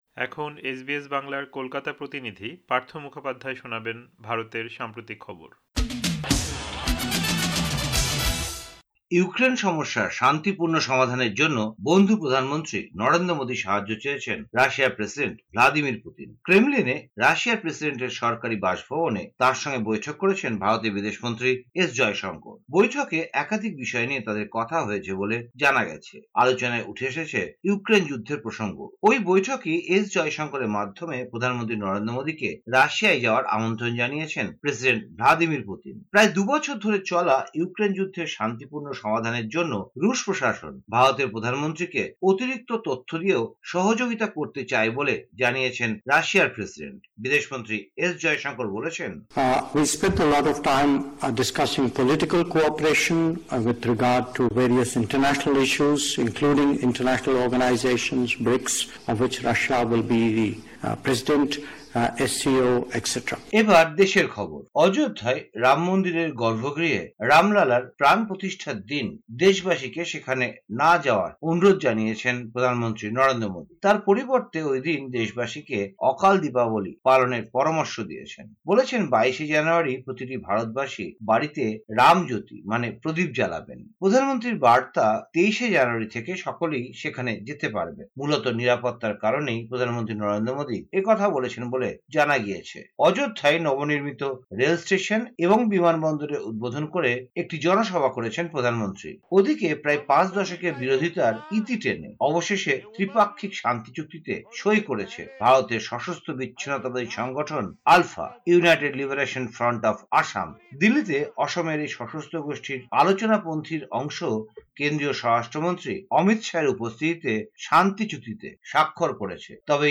ভারতের সাম্প্রতিক খবর: ১ জানুয়ারি, ২০২৪